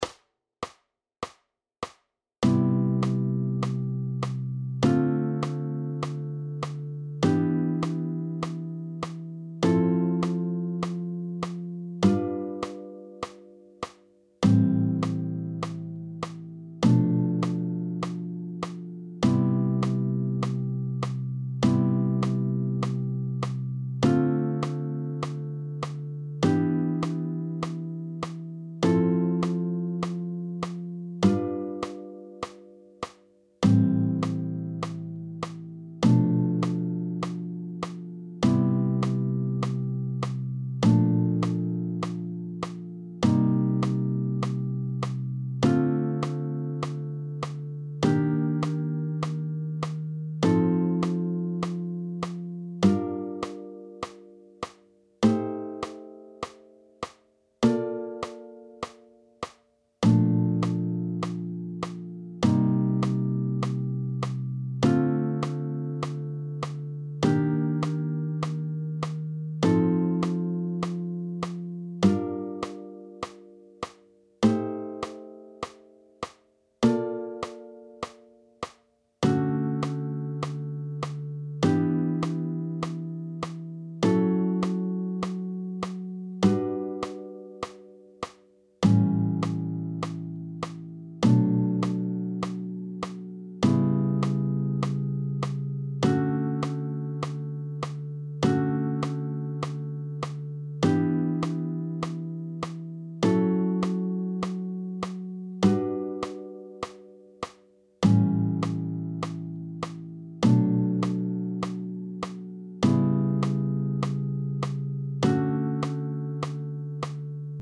I.) 4stimmige Jazzchords
I.2.) Grundton A-Saite:
Stufenakkorde in C-, Bb- und D-Dur + PDF
Jazz-Stufenakkorde-4stimmig-Grundton-A-Saite.mp3